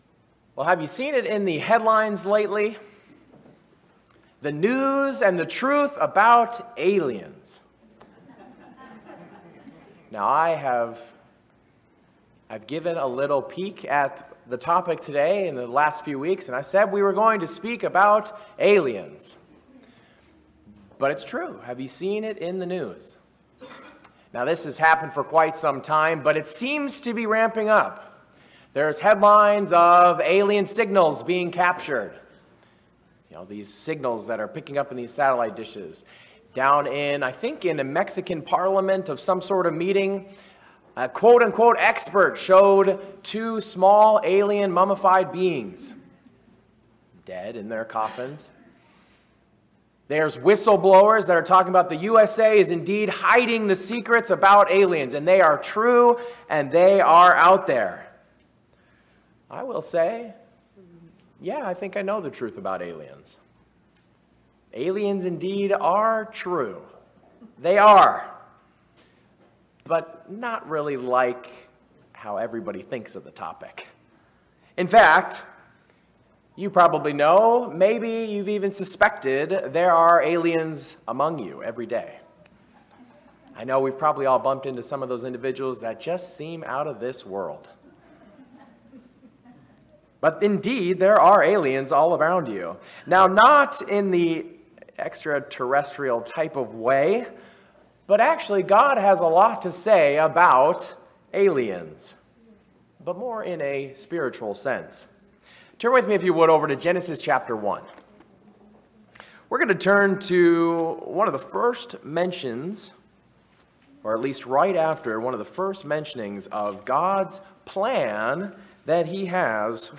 Sin separates you from God. The sermon reviews the meaning of the Day of Atonement and how God, through Christ, will reconcile the world to Himself.